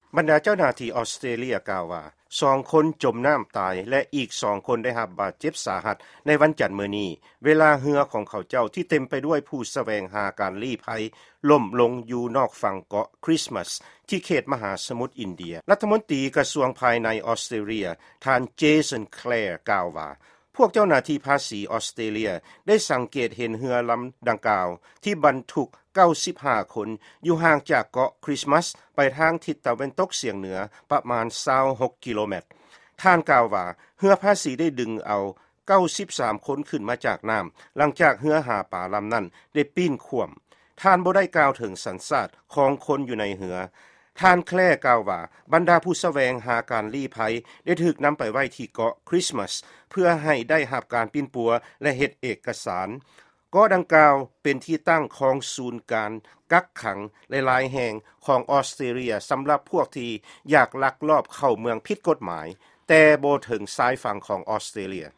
ຟັງຂ່າວ ເຮືອຫລົ້ມທີ່ ອອສເຕຣເລຍ